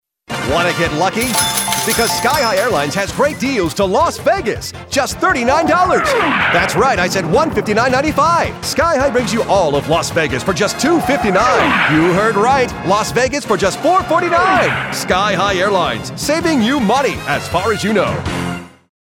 Radio Spots